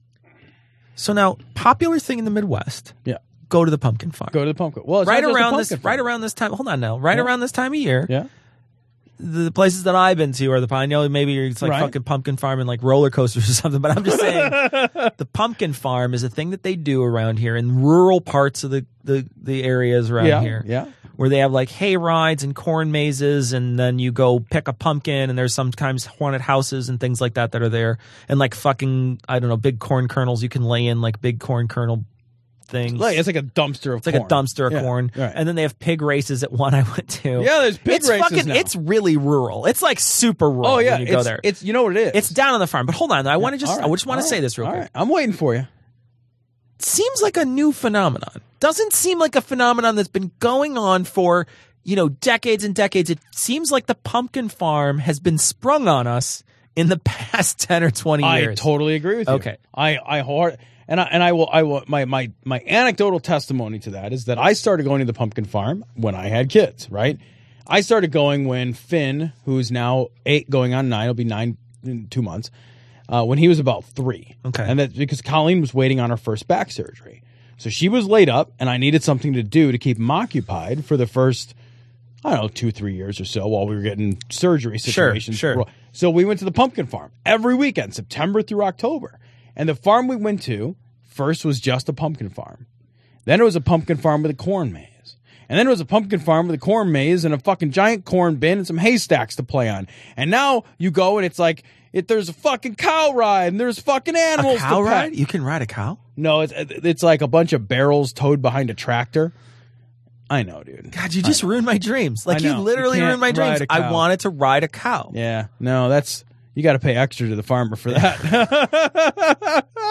A bit of chatter before we started covering stories for the week.